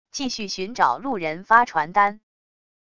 继续寻找路人发传单wav音频生成系统WAV Audio Player